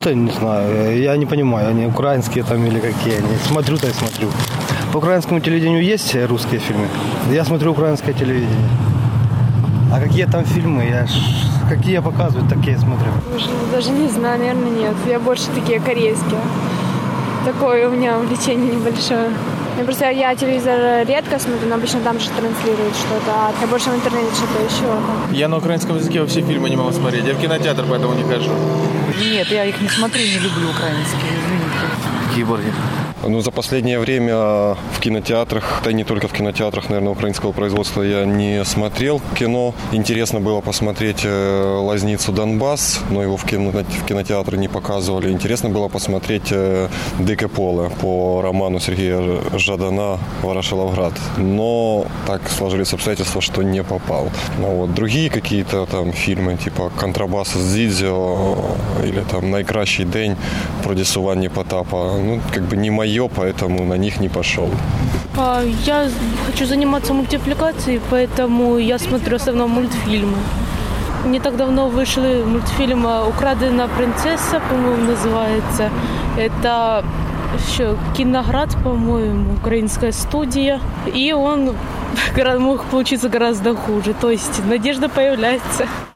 Сєвєродонецьк: Які українські фільми ви подивилися у 2018-му? (опитування)
Та чи дивилися ці фільми жителі сходу? Запитуємо у сєвєродончан (Луганська область).